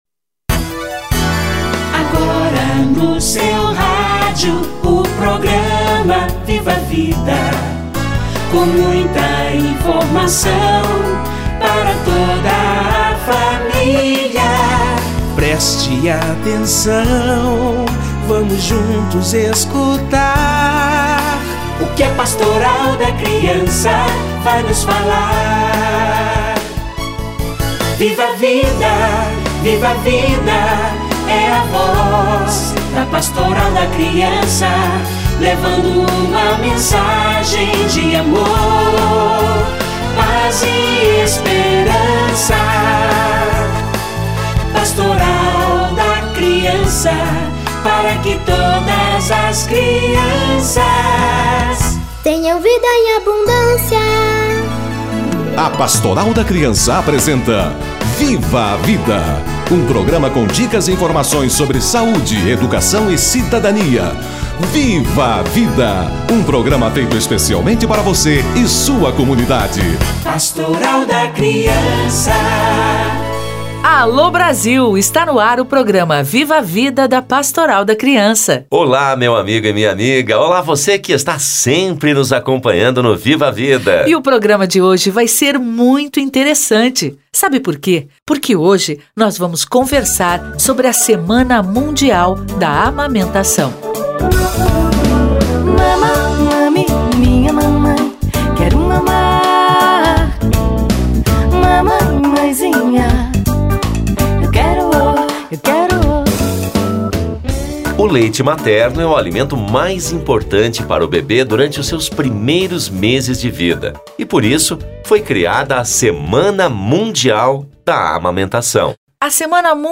Semana mundial da amamentação - Entrevista